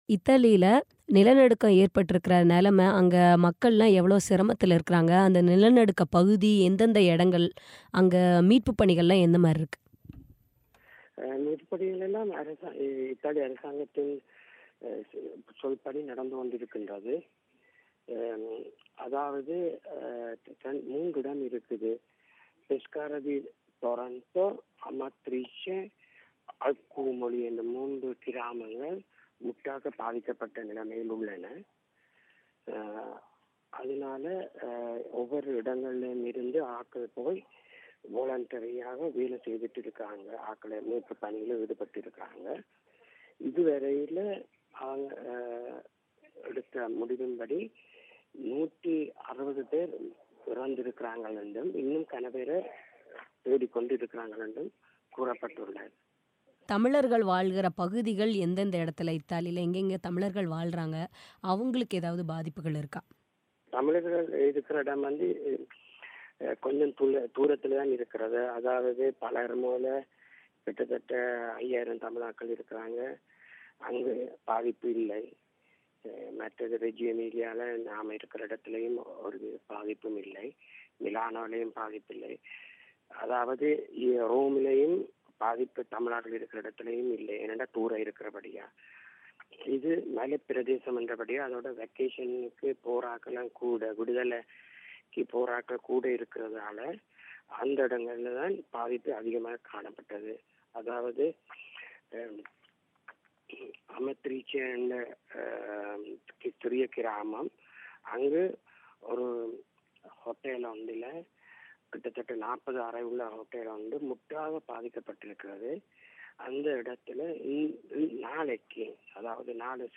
இத்தாலியில் ஏற்பட்டுள்ள நிலநடுக்கம் குறித்து அங்குள்ள தமிழரின் பேட்டி